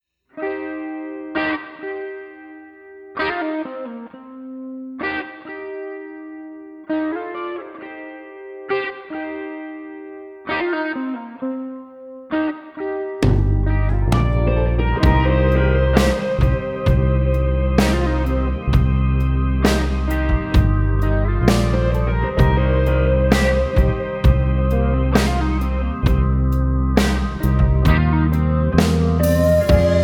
Guitars and Vocals
Bass and Keys